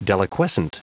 Prononciation du mot deliquescent en anglais (fichier audio)
Prononciation du mot : deliquescent